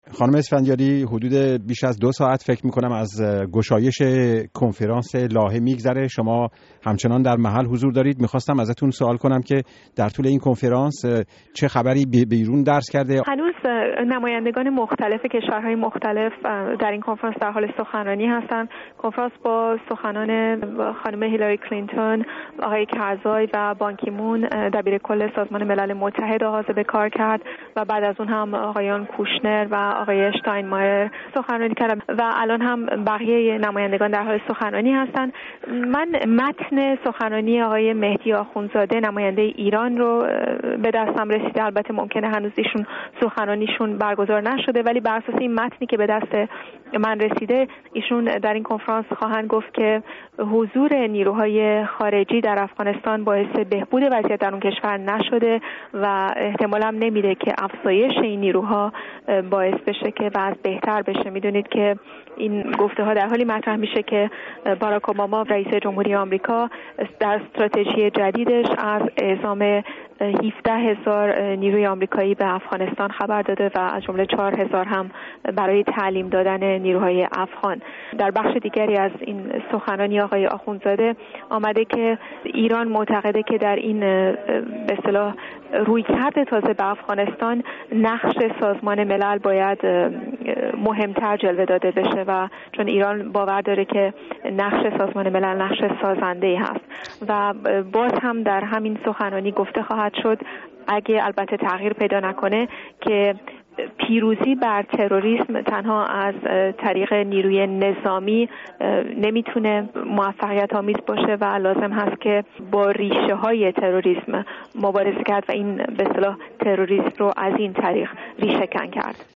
گفت و گو
در محل کنفرانس لاهه